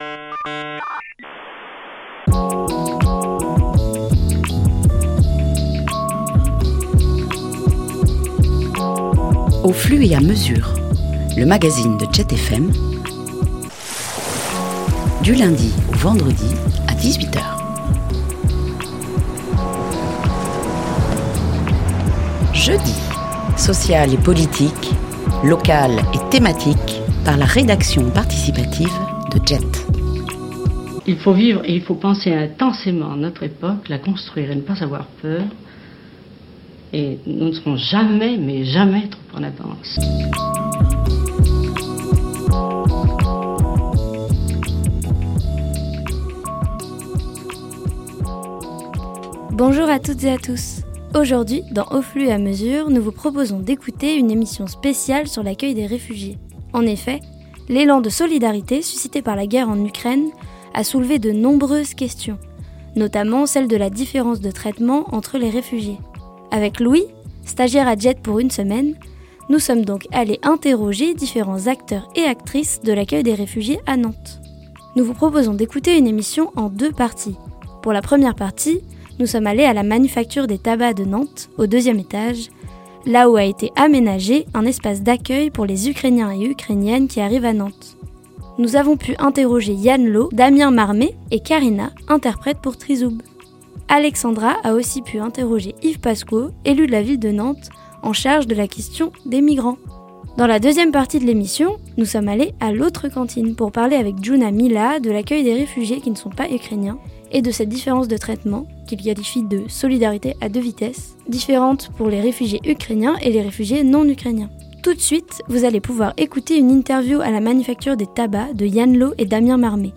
Emission spéciale sur l'accueil des réfugié-es
Interviews à la Manufacture des Tabacs
Interview à l’Autre Cantine